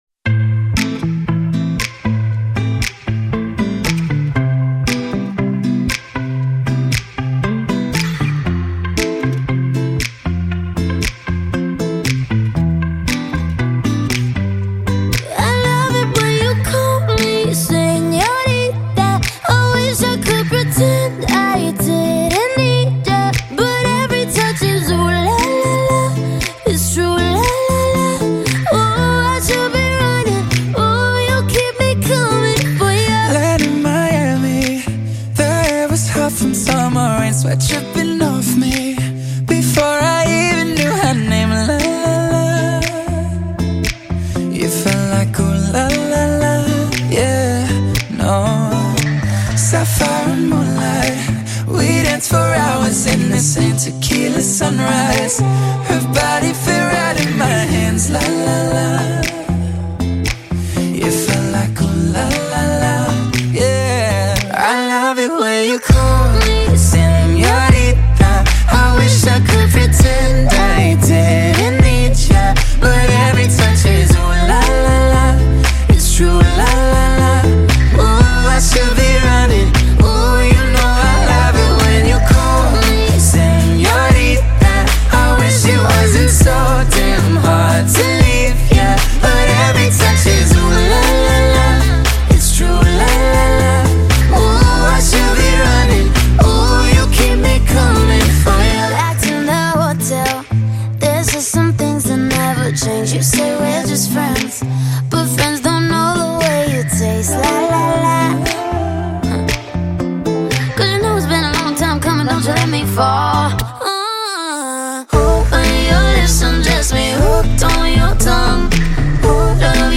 English Song